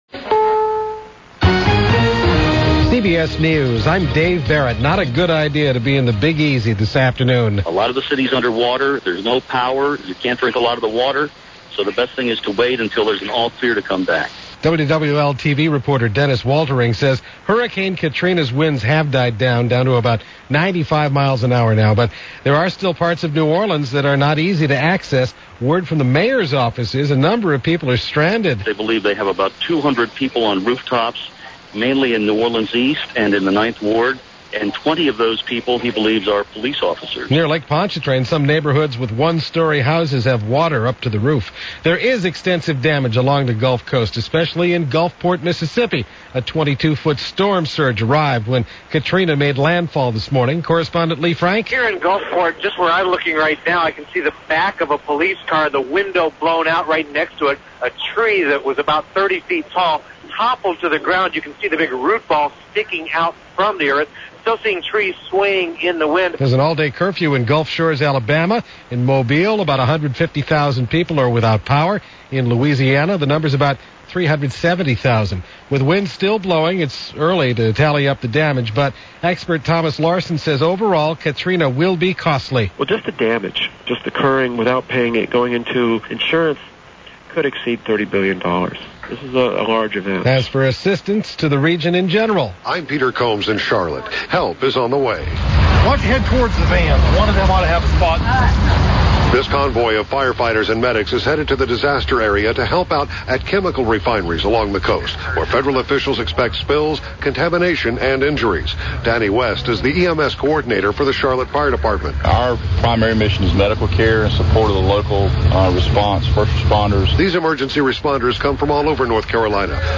– CBS News – August 29-30, 2005 – Gordon Skene Sound Collection –